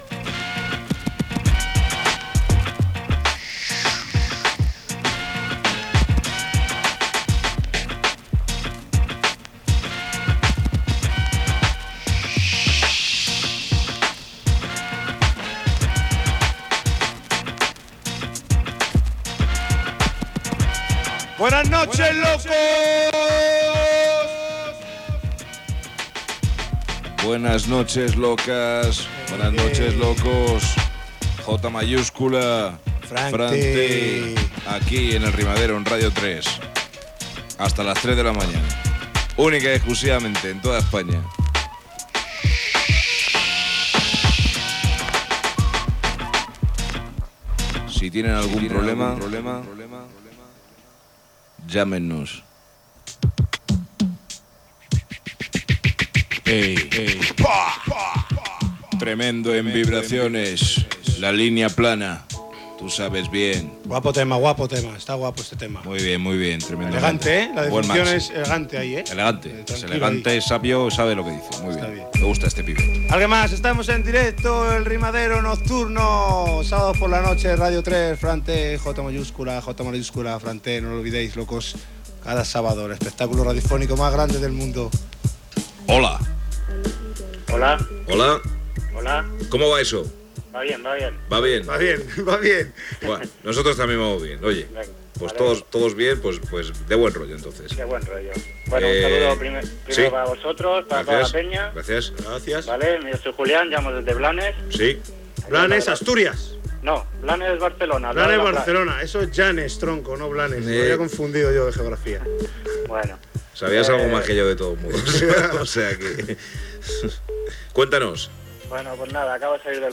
Presentació del programa i entrada d'una trucada en directe.
Musical